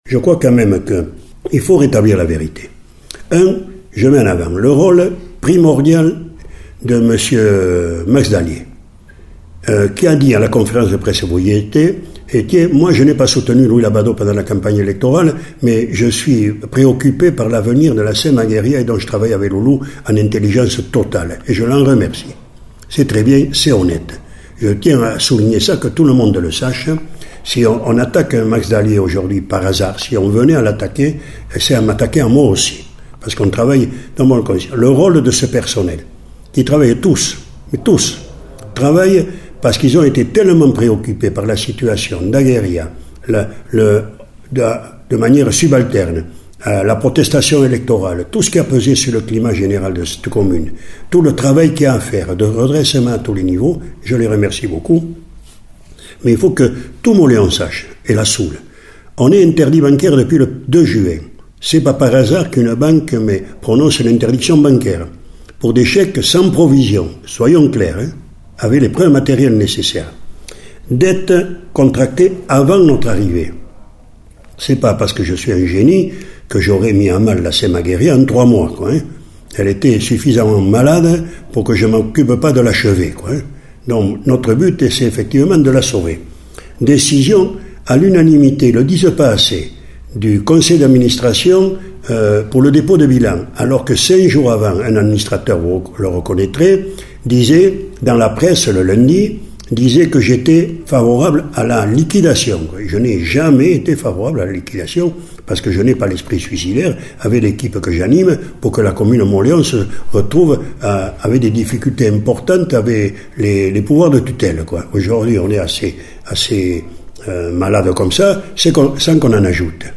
Louis Labadot: alkarrizketa osoa-